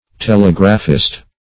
telegraphist - definition of telegraphist - synonyms, pronunciation, spelling from Free Dictionary
Telegraphist \Te*leg"ra*phist\, n.